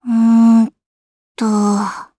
Gremory-Vox_Think_jp.wav